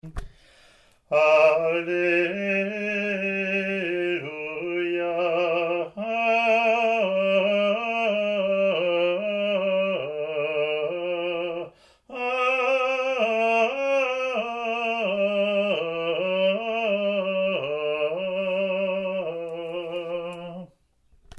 Alleluia Acclamation 1